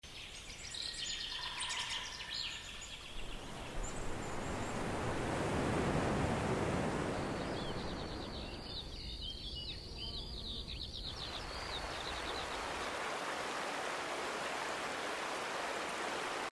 In this nature short video, I captured 4 relaxing ambience sounds of nature across Germany and Portugal. From peaceful meadow sounds with distant chirping birds to a quiet riverside with gentle stream sounds, each scene offers an authentic moment of calm. You’ll also hear calming forest sounds deep in the woodland and the soothing ocean sounds of soft waves sound along a breezy coastline. These real sounds of nature were recorded on location without any loops or artificial effects, just pure, layered ambient sound that invites you to pause and reconnect.